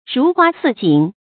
如花似錦 注音： ㄖㄨˊ ㄏㄨㄚ ㄙㄧˋ ㄐㄧㄣˇ 讀音讀法： 意思解釋： 錦：有彩色花紋的絲織品。